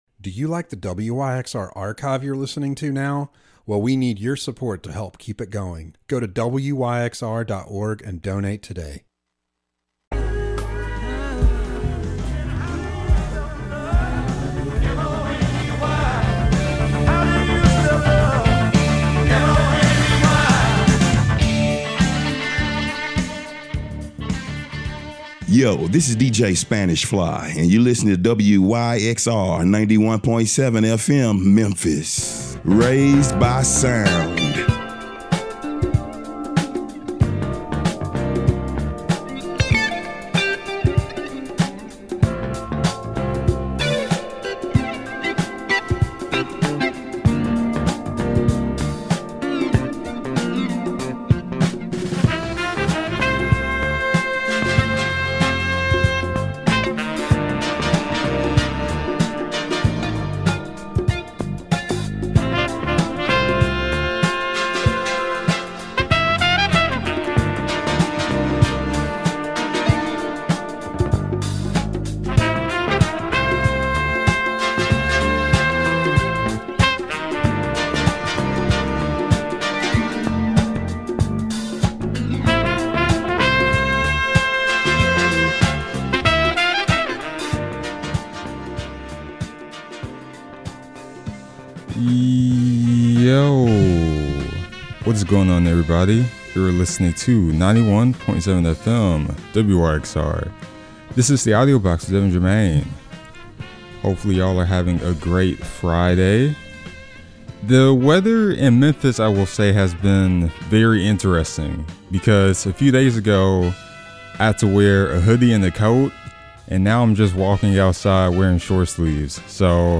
Expect a mix of interesting insights, eclectic battles, and the coolest tunes, making "The Audio Box" your new Friday afternoon rhythm and the perfect audio escape.
Soul Funk Pop